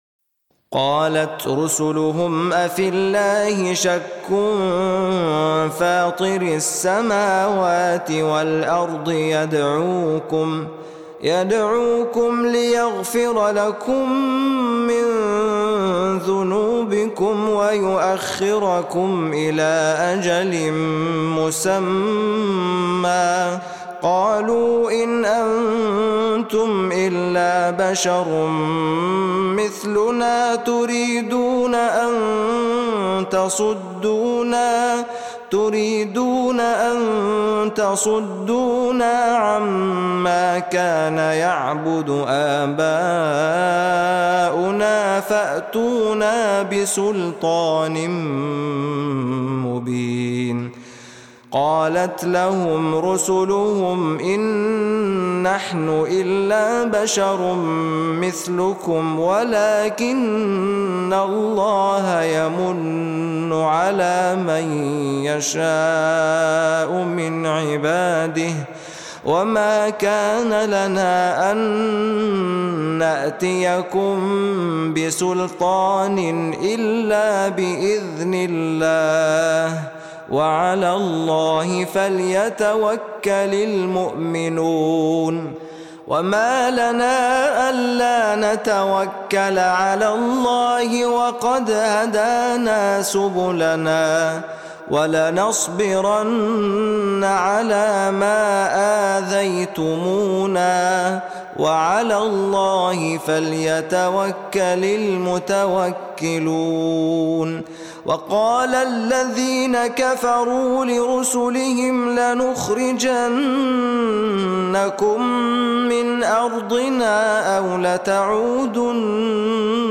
Besucher Rezitationen 1691 Hören 0 Gefällt mir Gefällt mir Teilen Herunterladen Andere Code einbetten Fehler melden ladet...